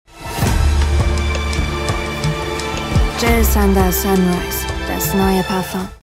plakativ, sehr variabel
Jung (18-30)
Commercial (Werbung)